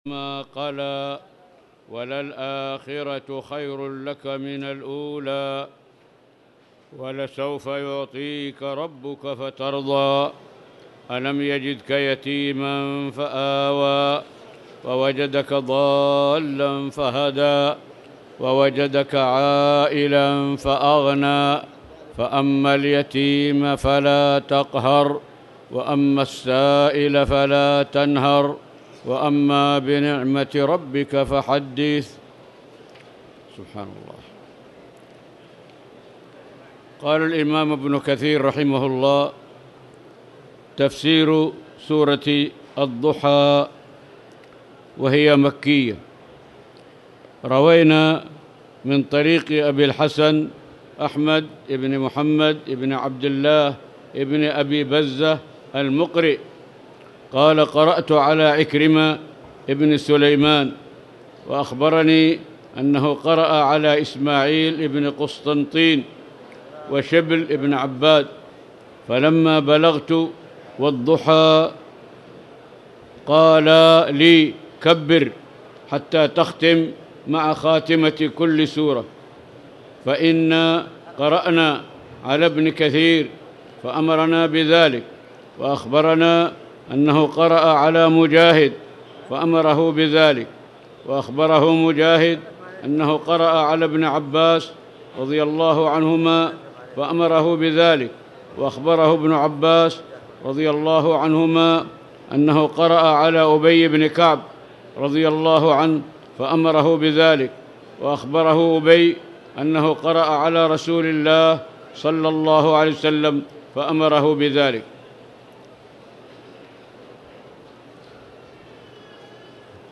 تاريخ النشر ٥ رمضان ١٤٣٧ هـ المكان: المسجد الحرام الشيخ